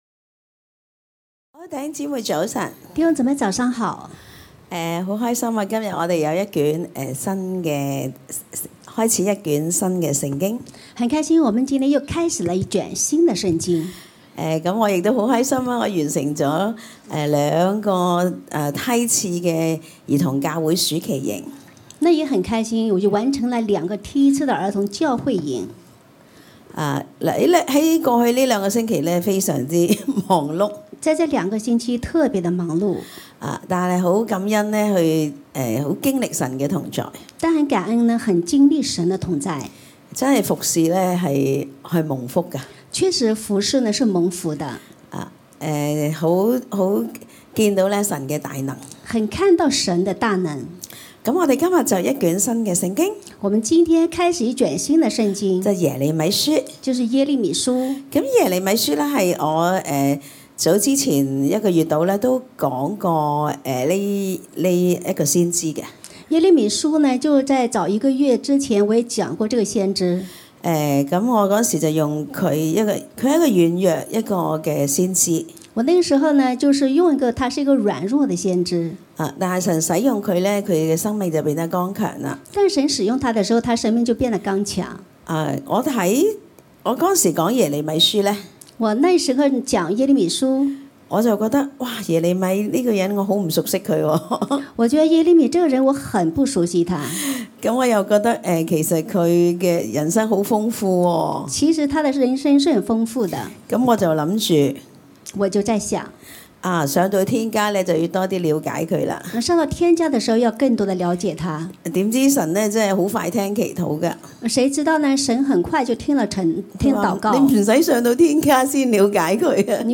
a.神的揀選：我們要用神眼光看自己。 b.領受先知禱告：小组長站在台前先領受先知啟示，為會眾個別發预言。 c.安静禱告：我們安静自己，為自己所聽到的预言禱告，讓我們更明白神的話。